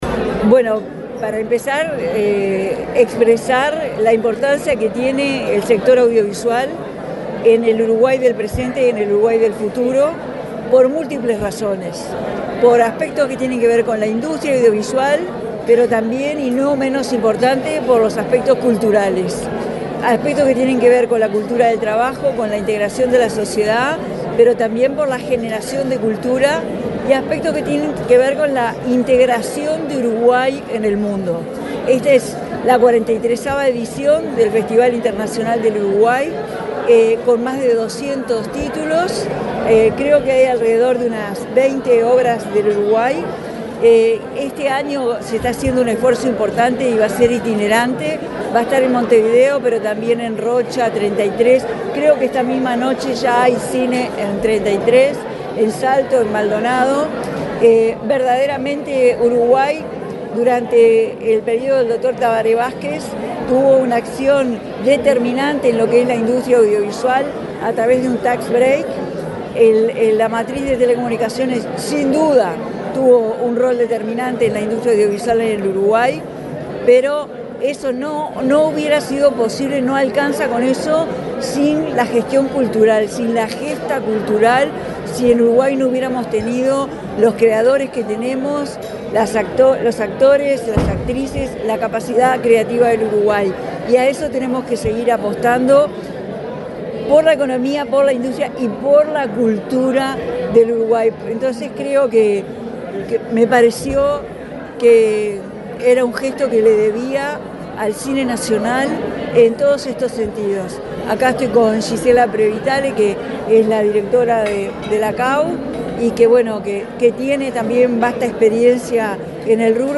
Declaraciones de la presidenta de la República en ejercicio, Carolina Cosse
Declaraciones de la presidenta de la República en ejercicio, Carolina Cosse 08/04/2025 Compartir Facebook X Copiar enlace WhatsApp LinkedIn La presidenta de la República en ejercicio, Carolina Cosse, dialogó con la prensa, antes de participar del acto de apertura de la 43.ª edición del Festival Cinematográfico Internacional de Uruguay.